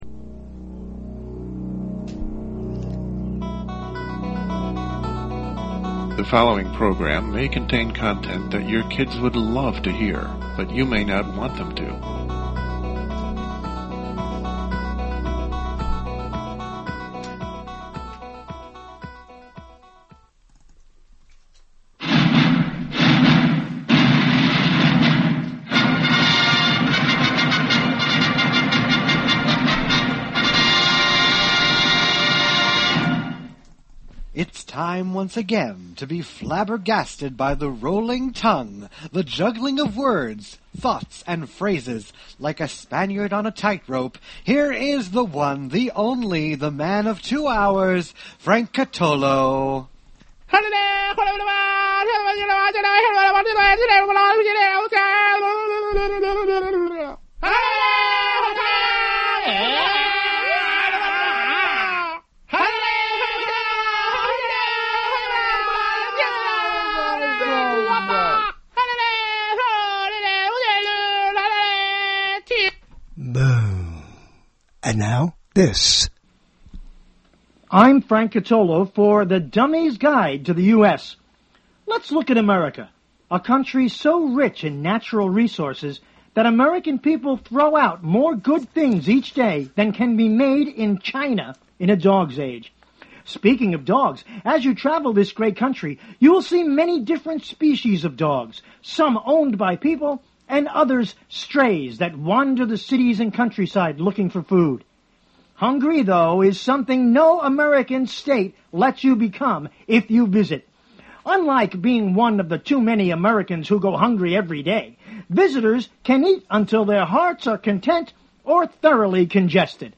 He talks with our host about the book and exchanges tales of Hollywood on the June 20 show.